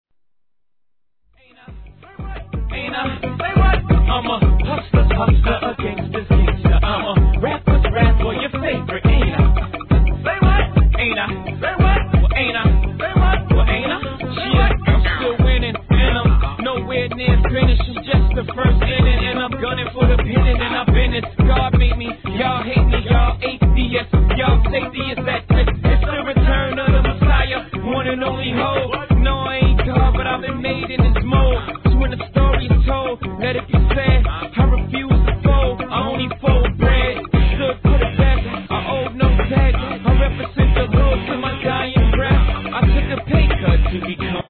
HIP HOP/R&B
(BPM87)